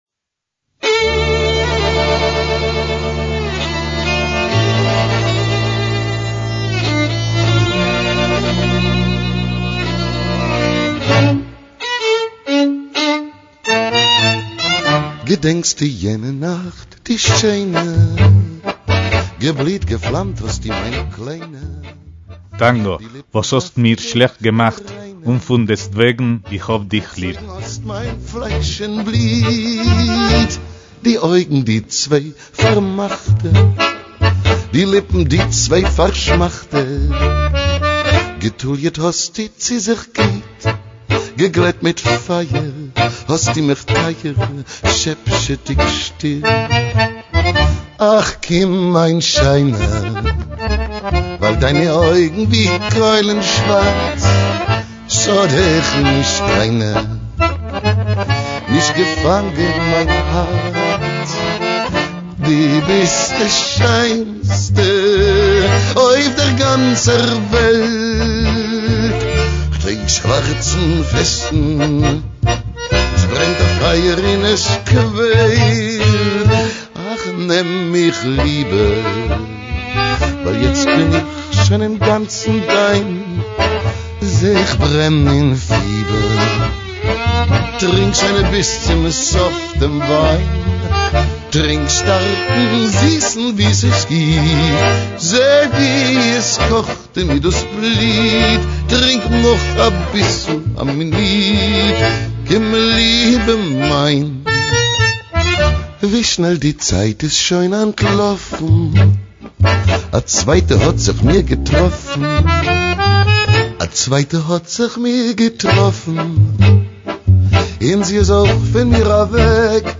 MÚSICA ÍDISH - El tango, la música de los burdeles y barrios bajos del Río de la Plata, prendió como un reguero de pólvora a inicios del siglo XX por toda Europa.